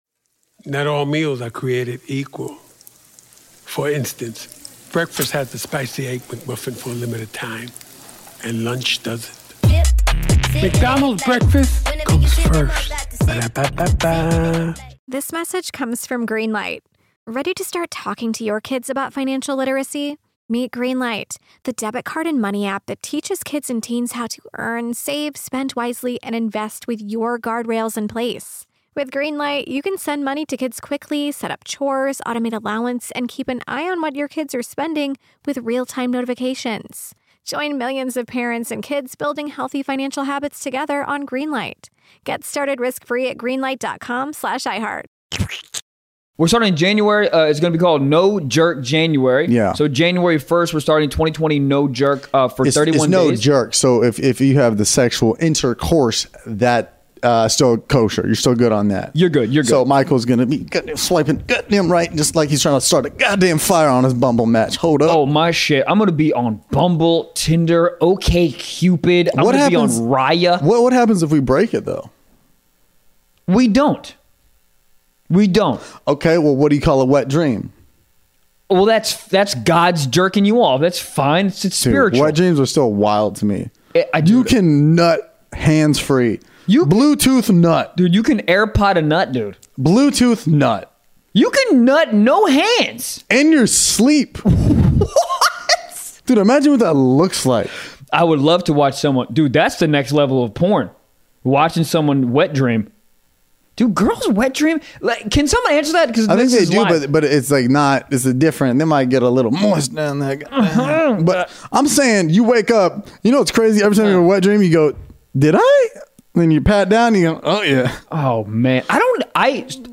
The bois went live on Patreon. They answered your questions about the origin of the podcast, the crazy shit they've seen at frat parties, whether losing a nut is worth a used M3, and a whole lot more.